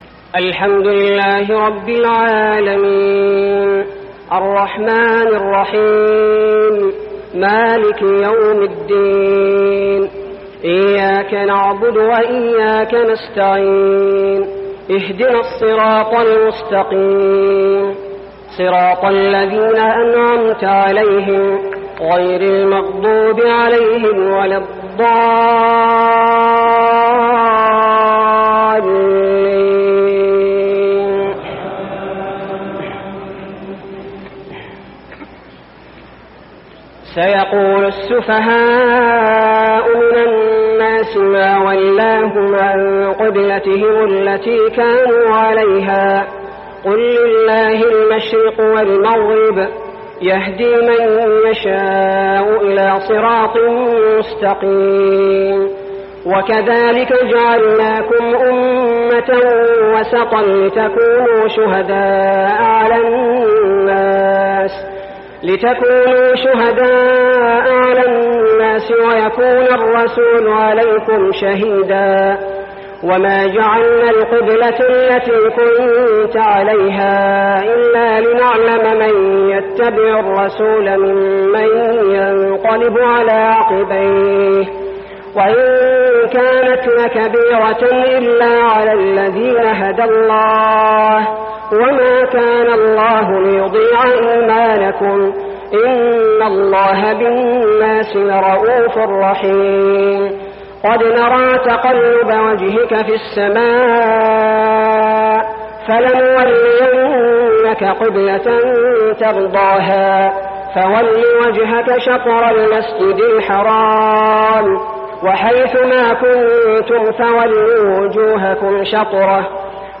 صلاة التراويح ليلة 2-9-1411هـ سورة البقرة 142-203 | Tarawih Prayer Surah Al-Baqarah > تراويح الحرم المكي عام 1411 🕋 > التراويح - تلاوات الحرمين